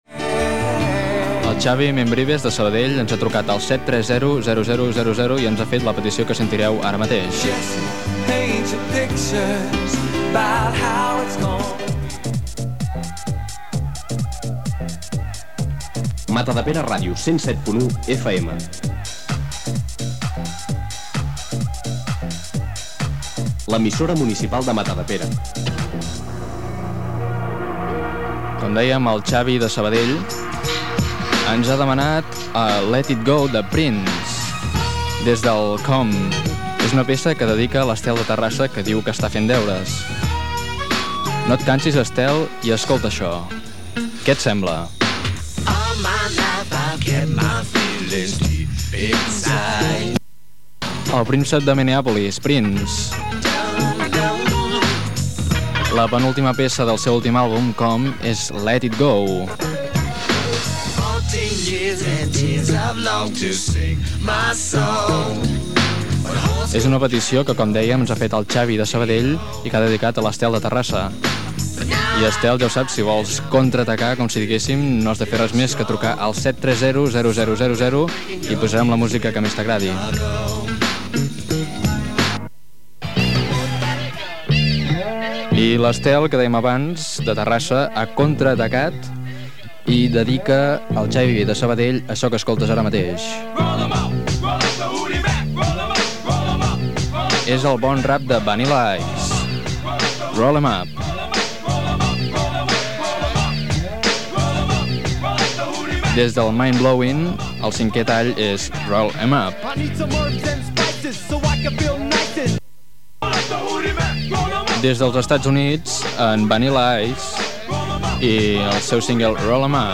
Indicatiu de l'emissora i presentació de temes musicals demanats pels oïdors. Identificació de l'emissora.
Musical